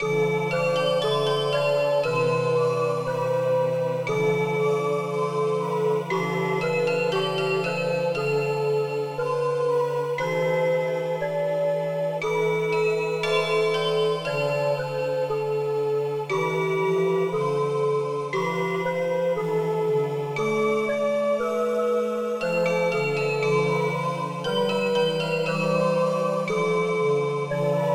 Žánr : elektronická hudba
Martenot wave + synth Crystal,Echo